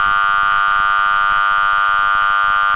ZUMBADOR - SONIDO CONTINUO
Zumbador electromagnético de fijación base/mural
Sonido continuo